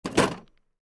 toolbox_close.ogg